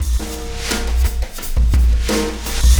Black Hole Beat 25.wav